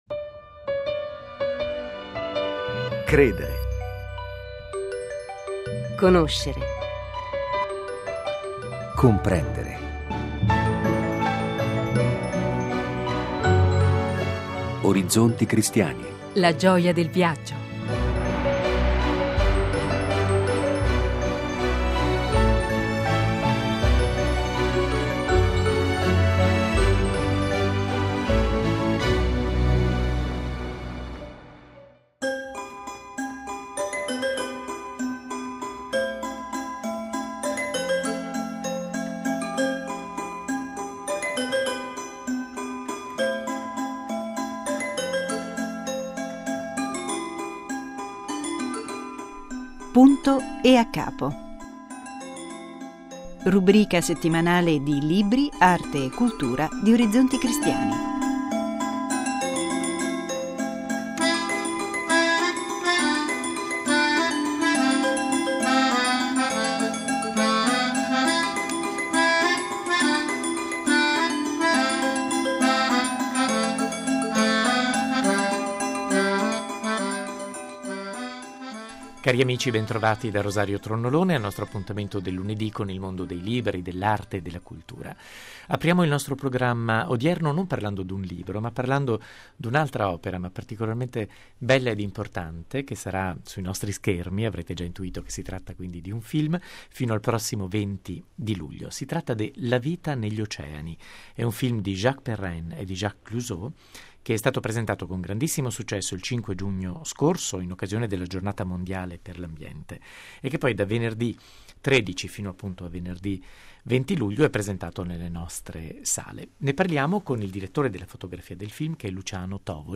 nostra ospite al telefono